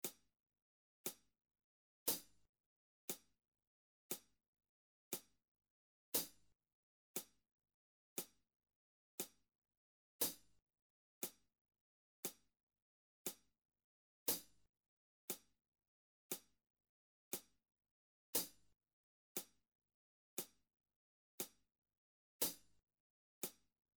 Minus All Guitars Rock 5:02 Buy £1.50